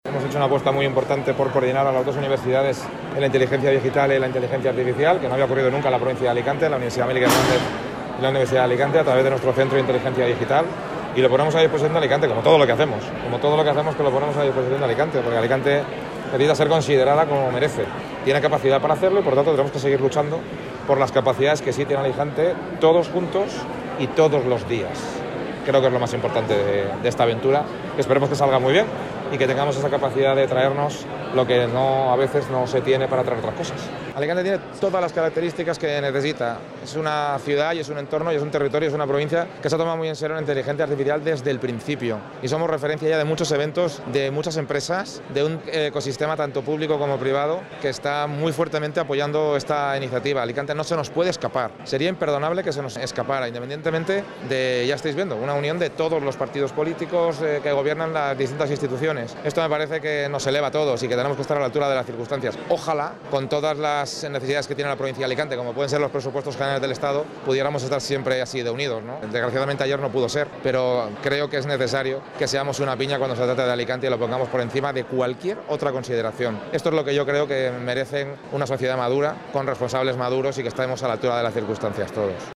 Al respecto, al término del acto celebrado en Distrito Digital y al que han asistido el presidente de la Generalitat, Ximo Puig, y el alcalde de Alicante, Luis Barcala, entre otras autoridades, Mazón ha reclamado esta misma unión para defender el resto de intereses de la provincia.